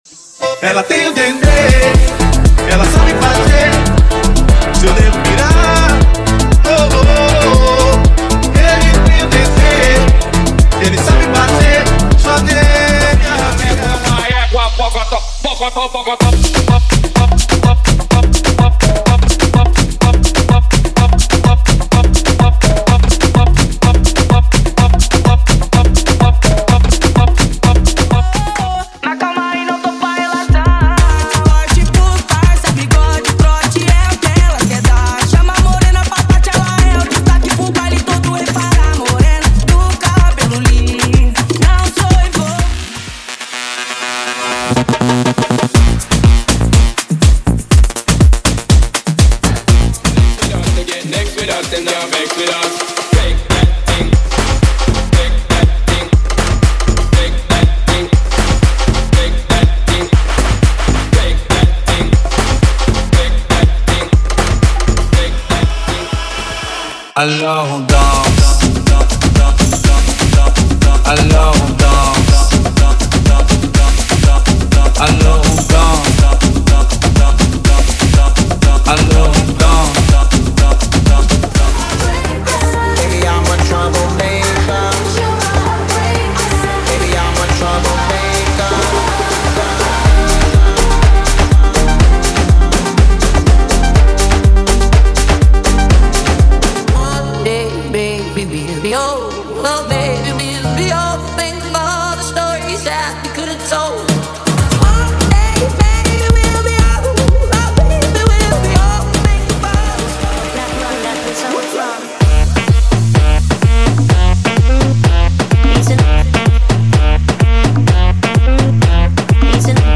Afro House: Prévias